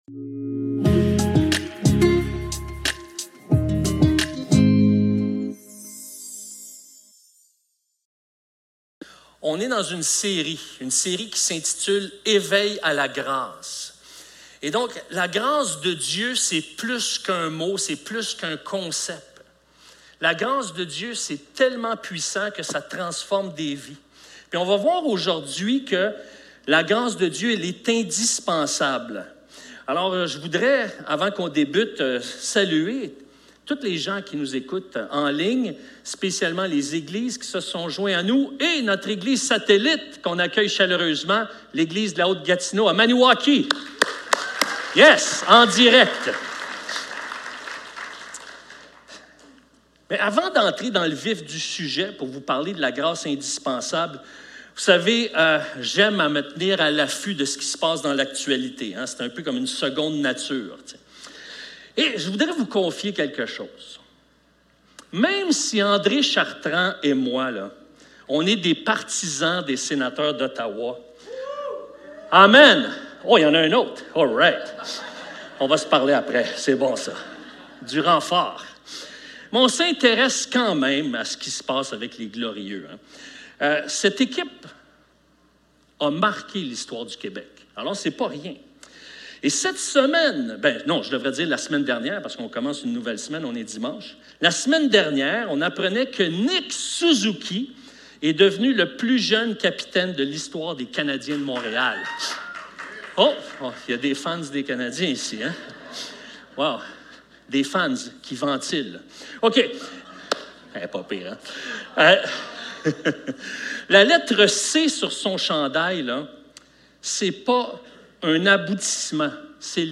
Service Type: Célébration dimanche matin